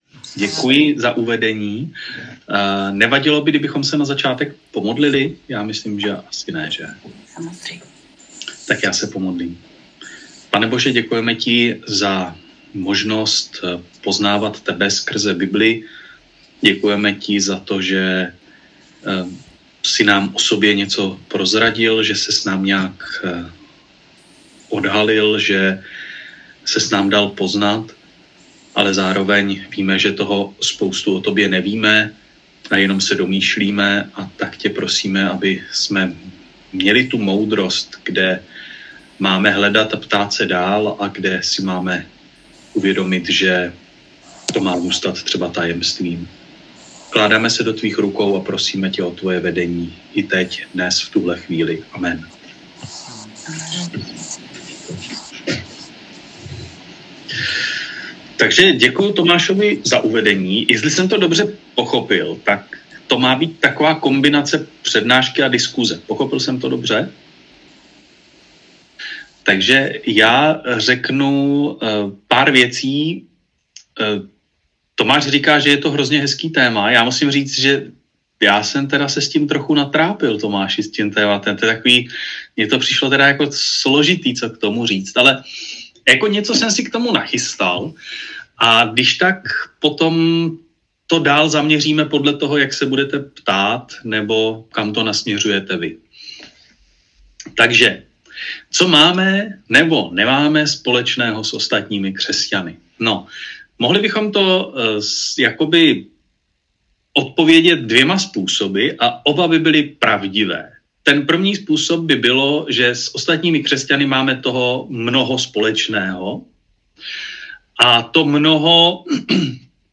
První přednáška z cyklu ČEMU VĚŘÍ ADVENTISTÉ
Zvukový záznam přednášky si můžete přehrát pod tímto příspěvkem.